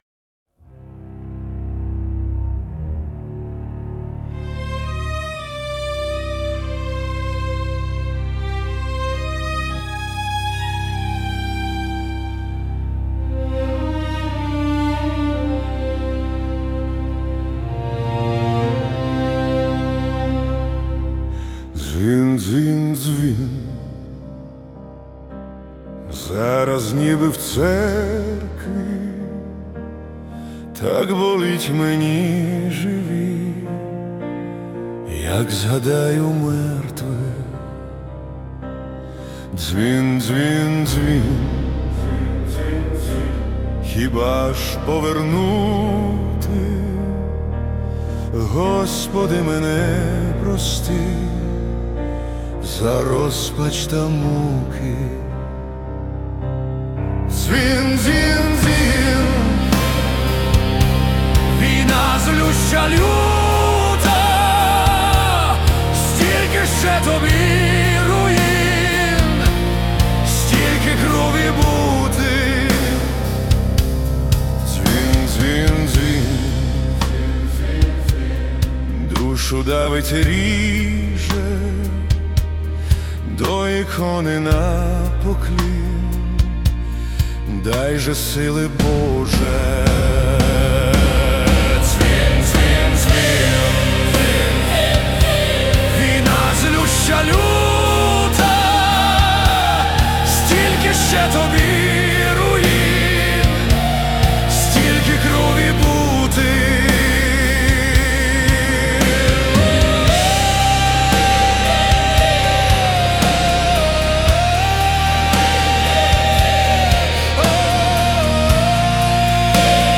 Музична композиція створена за допомогою SUNO AI